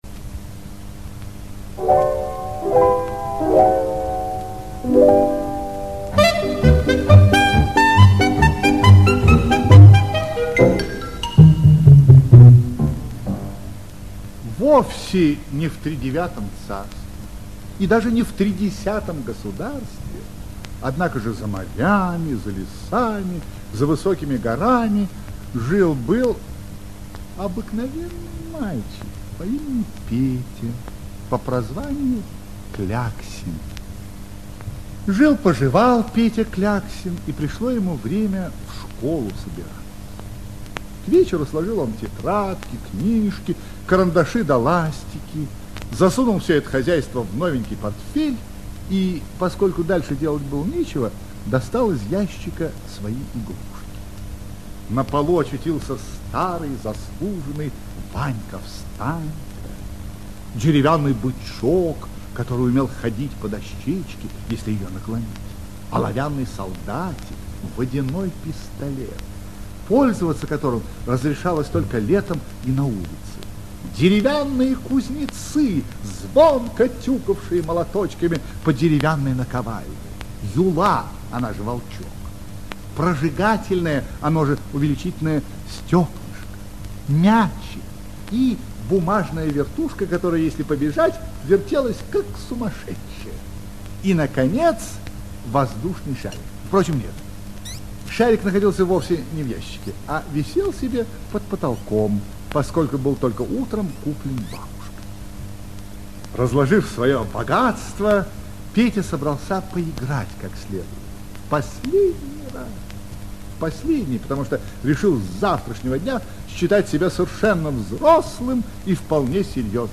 Как игрушки пошли учиться - аудиосказка коллектива авторов. Сказка про мальчика Петю Кляксина, который завтра станет первоклассником.